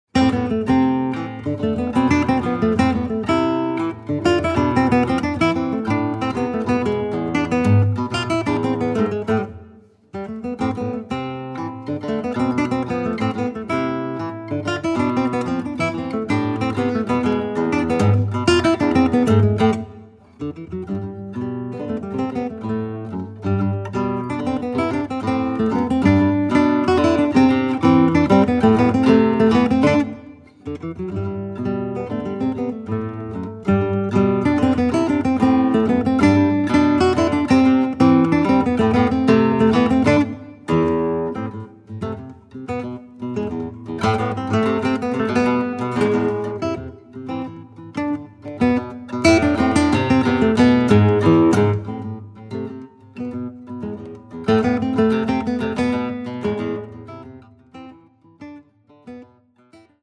the guitar duo.